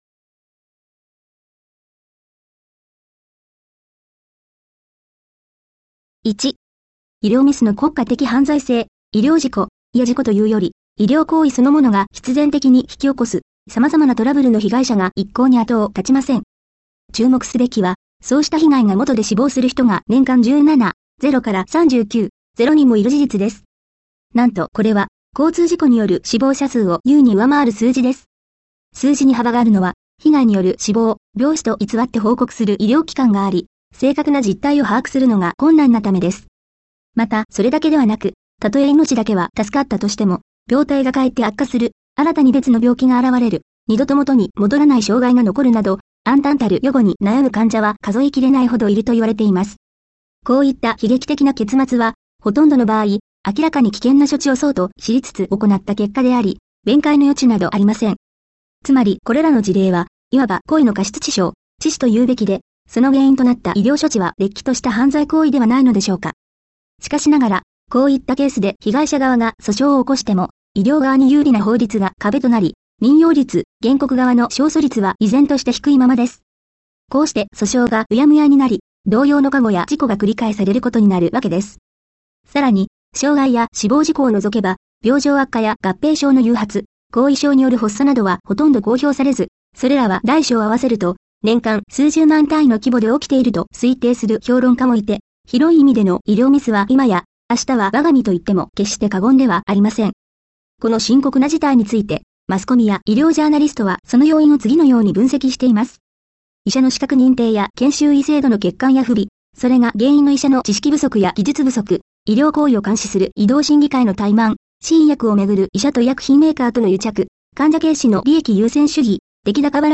医療ミスの真因と現代医学の根本的ミス（誤謬） 根本の矛盾を探ると・・・ 医療妄信の危険ＨＰ 魚拓より 音声読み上げ１